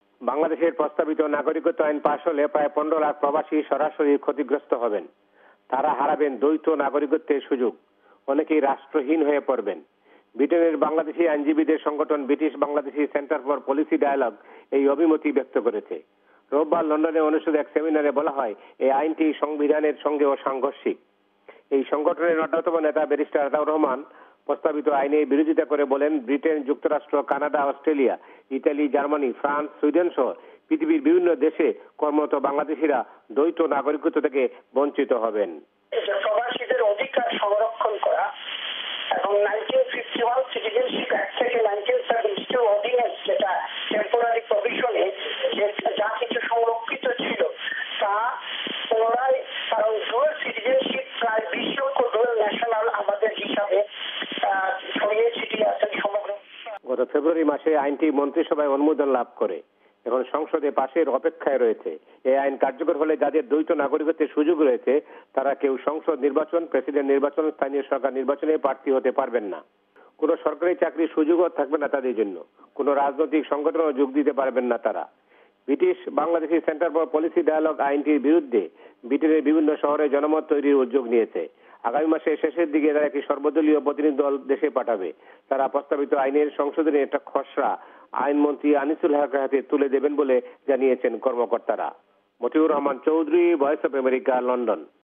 লন্ডন থেকে